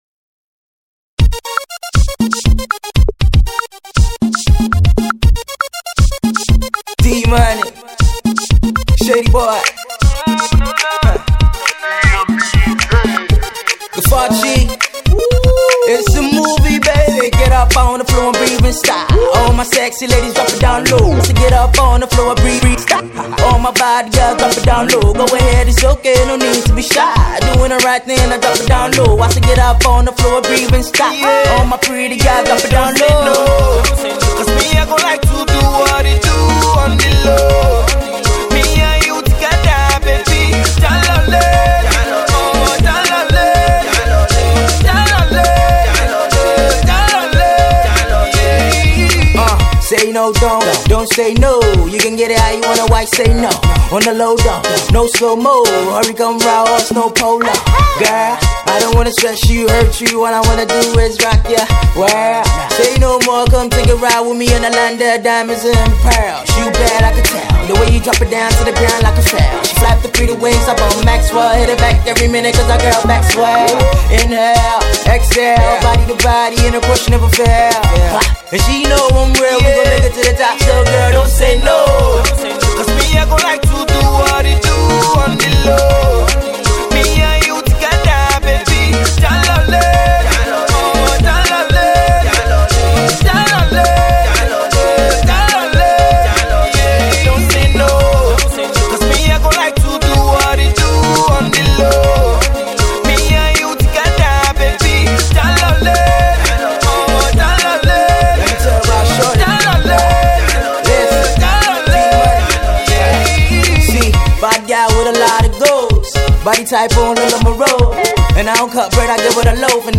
fun new skool energy and enjoyable rap flow